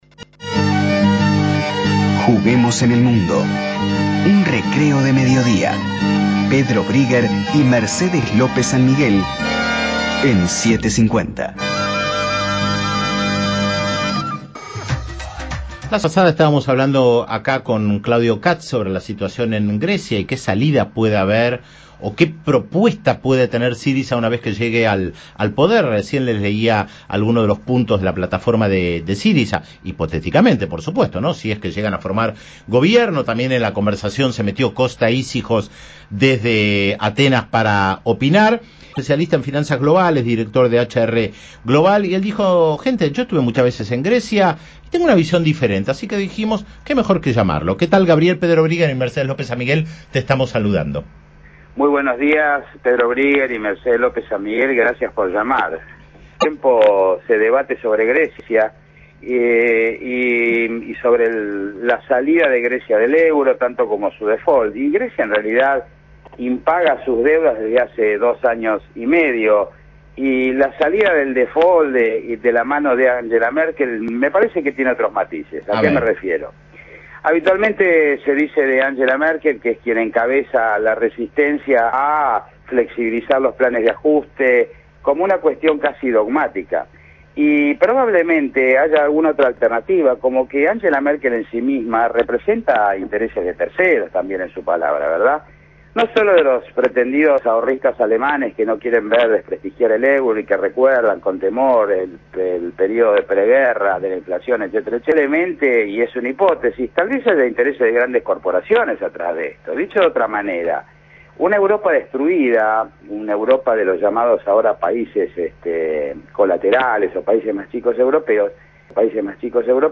Mi opinión acerca de la situación en Grecia Entrevista en AM 750